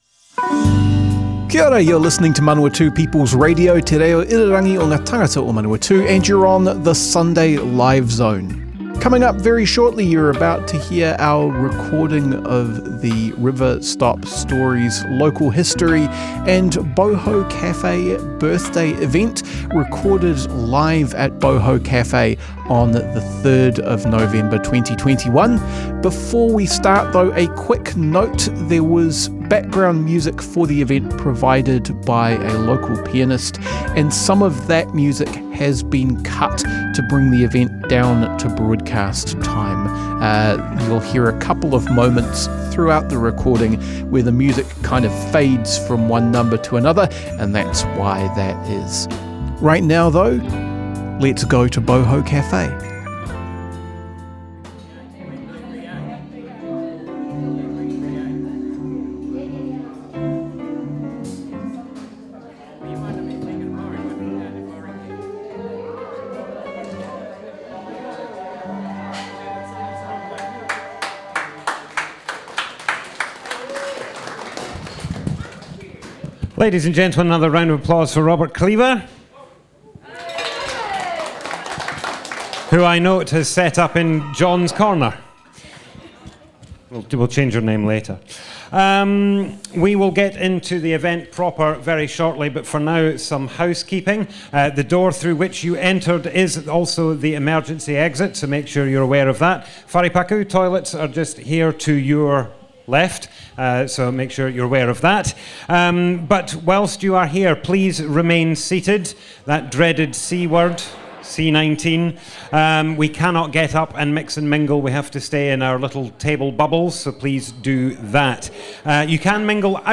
00:00 of 00:00 Add to a set Other Sets Description Comments Awapuni Music, Tea and Tales More Info → Description On Wednesday 3rd November 2021 River Stop Awapuni held an event at Boho Cafe to celebrate the Awapuni neighbourhood, as part of city-wide Local History Week celebrations.
Manawatū People's Radio were present at the event and have kindly shared their audio file of the event for our archives.
oral history